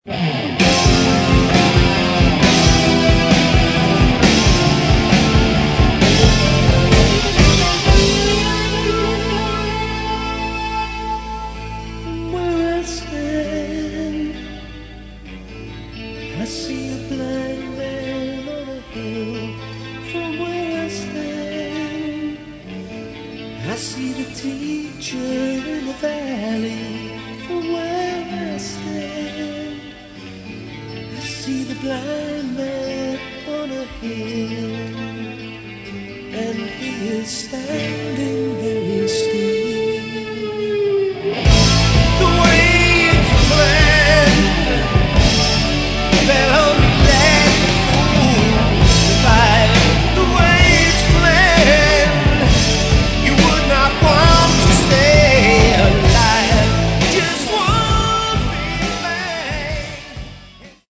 Рок
Плотный роковый бас и барабаны
клавишные
отличительные гитары